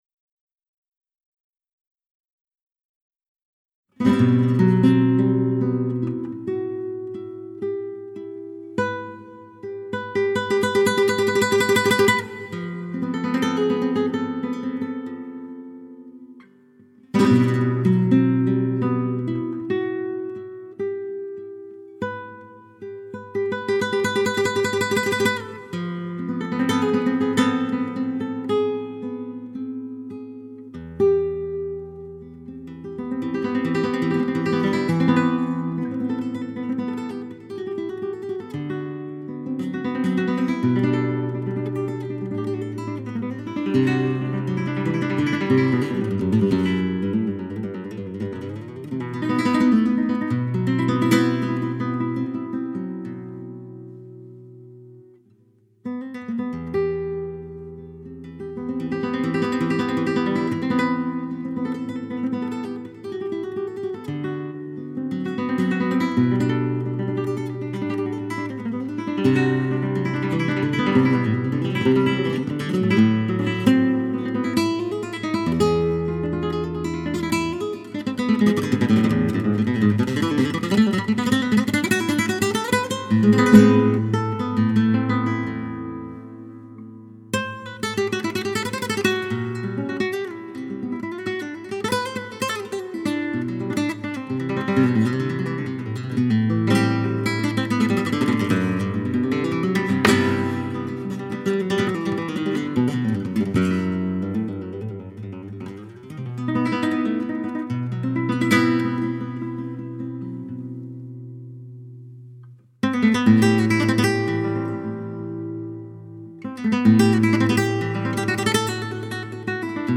Flamenco en estado puro
a la guitarra durante la Gala